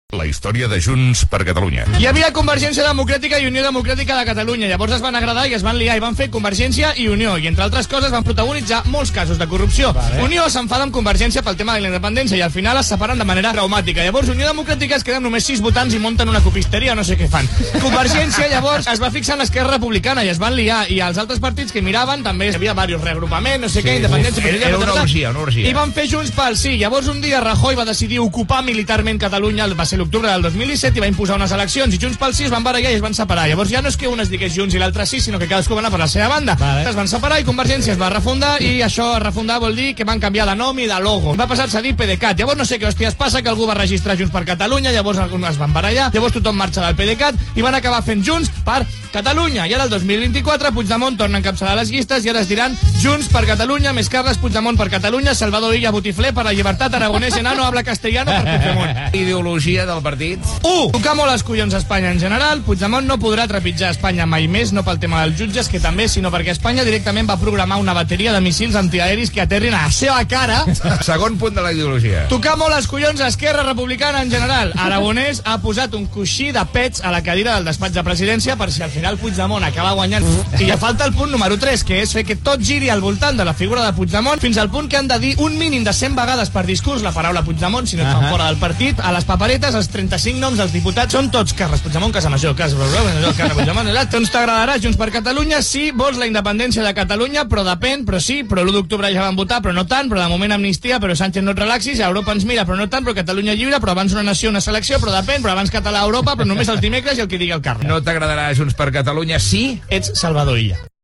Història del partit Junts per Catalunya Gènere radiofònic Entreteniment